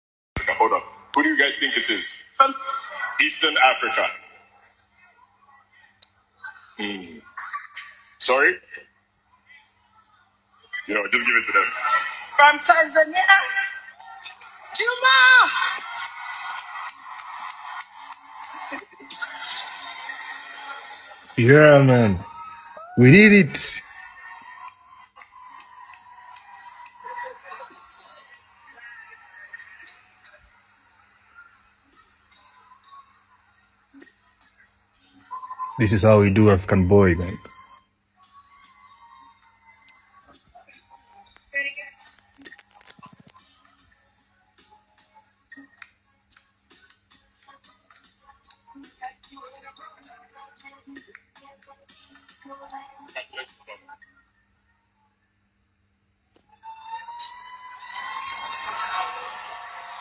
During the award ceremony
Eastern African pop with global influences